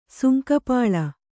♪ sunka pāḷa